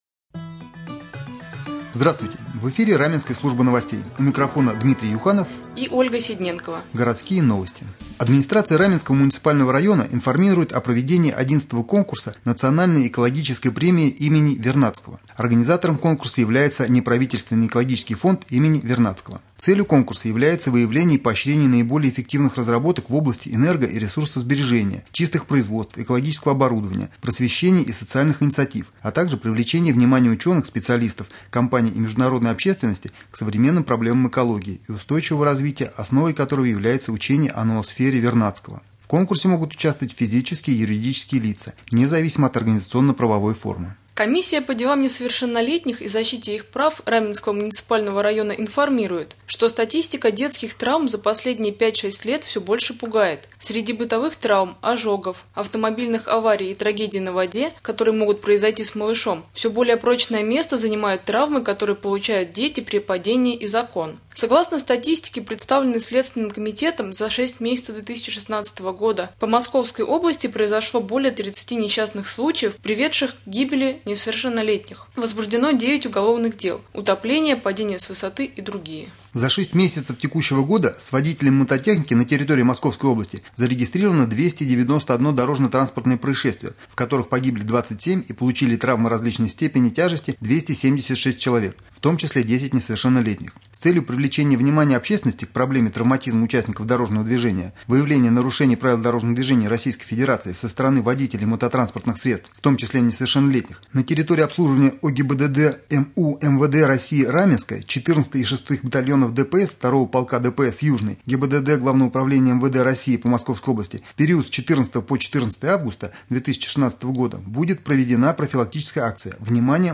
Новостной блок